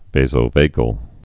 (vāzō-vāgəl)